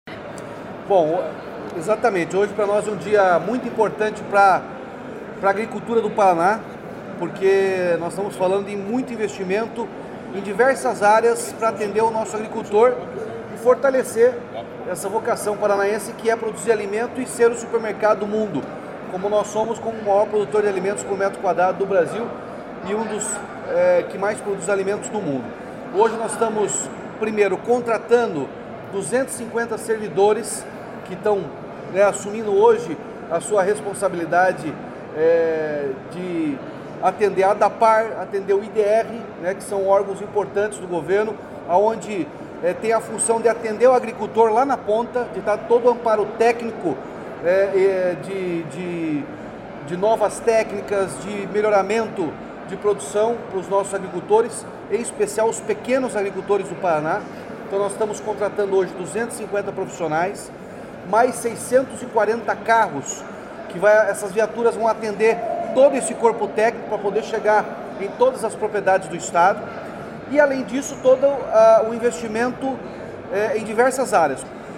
Sonora do governador Ratinho Junior sobre os 324 novos servidores e entrega de 250 veículos para o sistema de agricultura